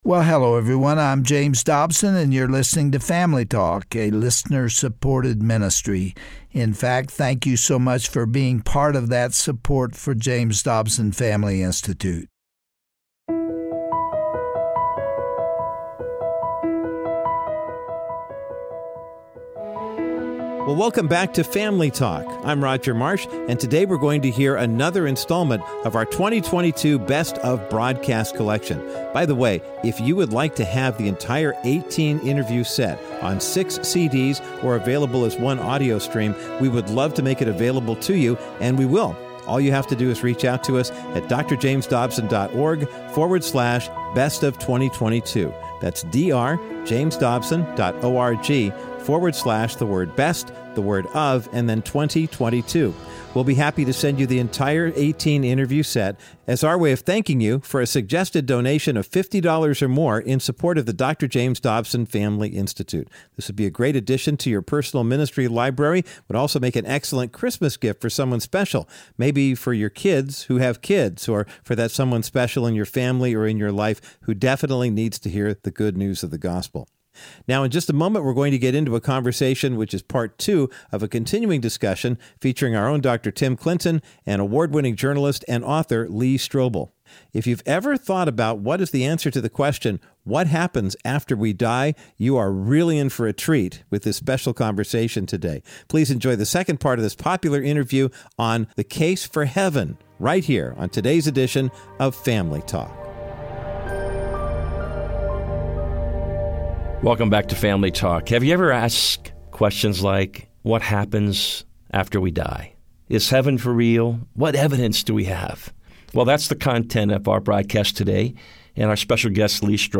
Guest(s):Lee Strobel